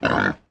Index of /App/sound/monster/wild_boar_god
damage_2.wav